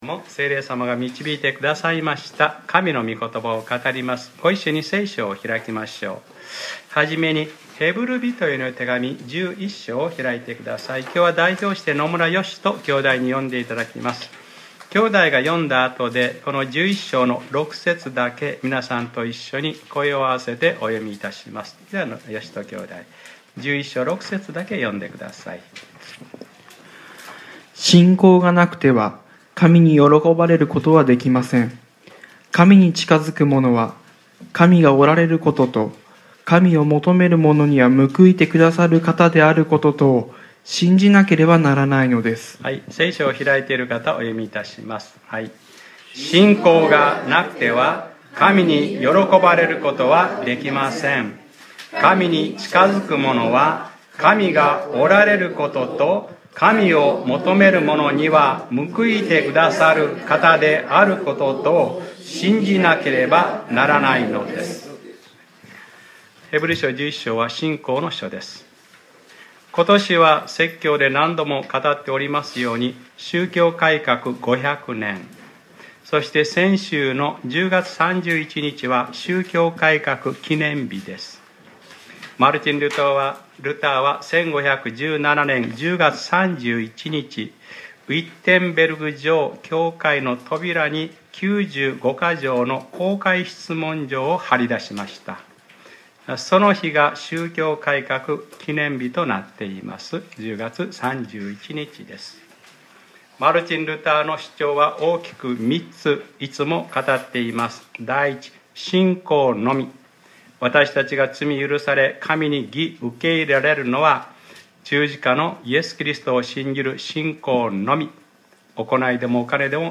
2017年11月05日（日）礼拝説教『信仰がなければ神に喜ばれることはありません』